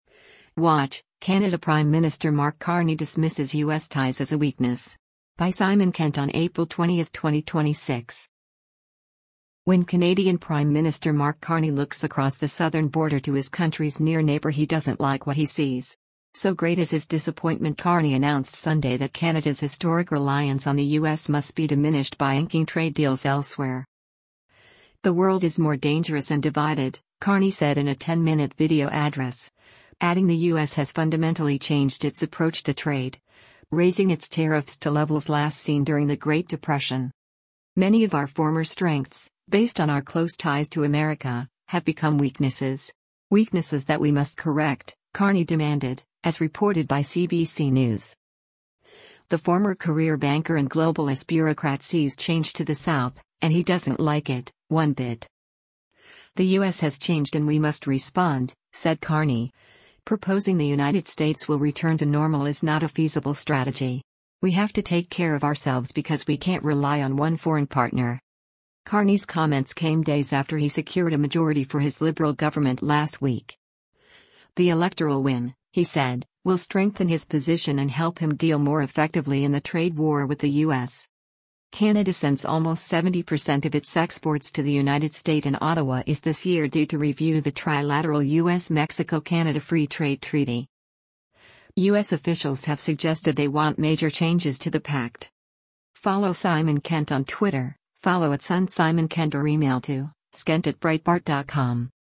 Canadian Prime Minister Mark Carney speaks during a press conference at the West Block on